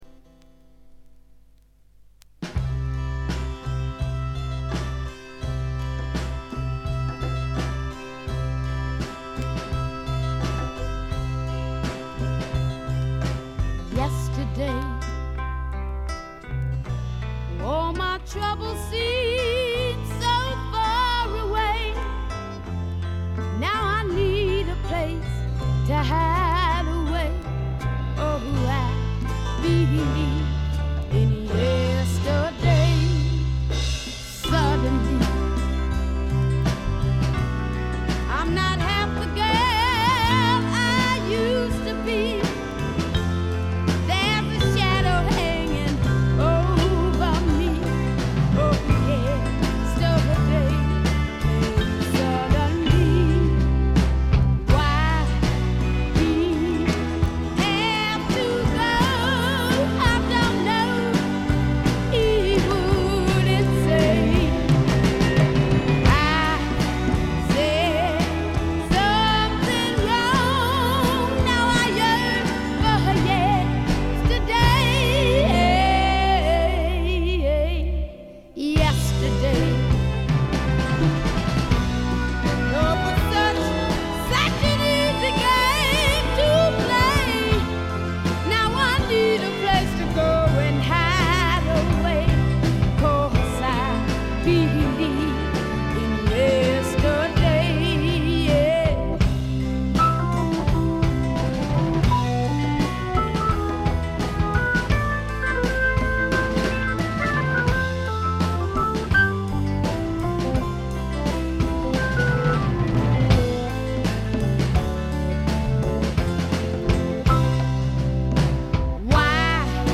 全体にバックグラウンドノイズ、チリプチ多め。
試聴曲は現品からの取り込み音源です。